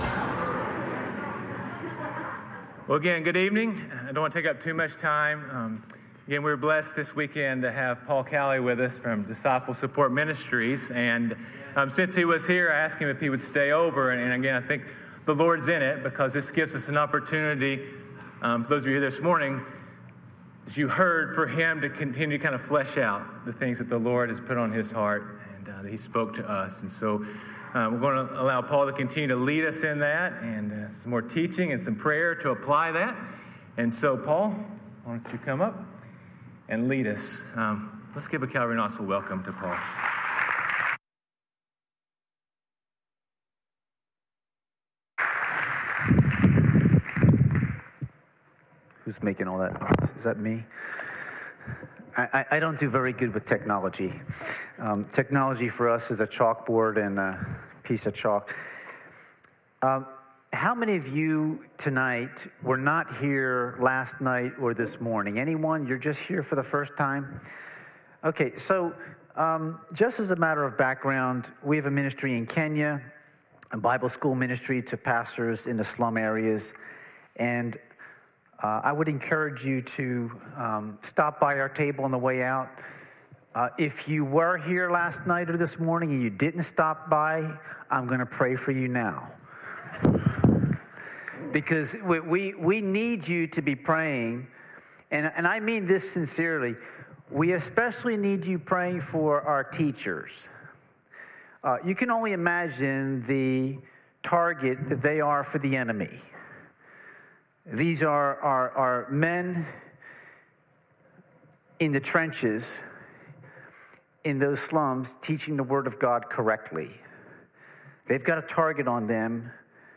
Calvary Knoxville Sunday PM Live!